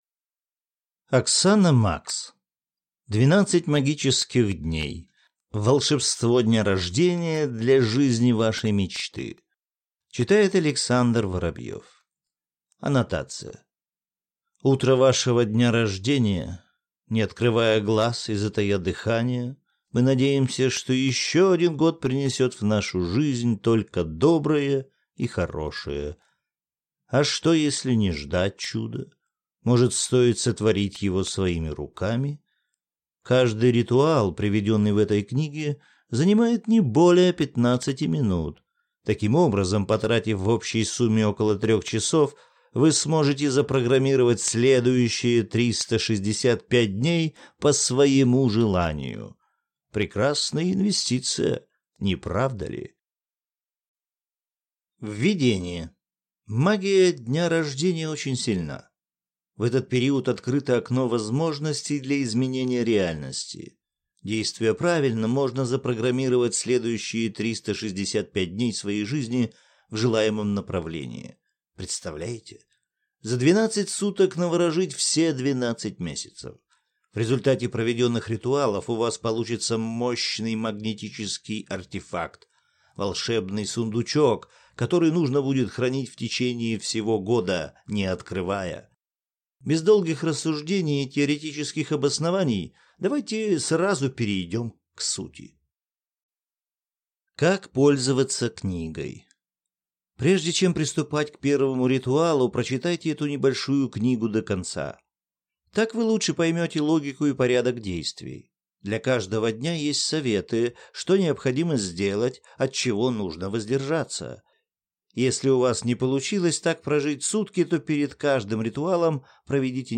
Аудиокнига 12 магических дней. Волшебство дня рождения для жизни вашей мечты | Библиотека аудиокниг